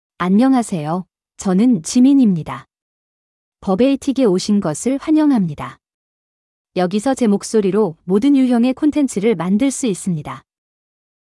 JiMinFemale Korean AI voice
JiMin is a female AI voice for Korean (Korea).
Voice sample
Listen to JiMin's female Korean voice.
Female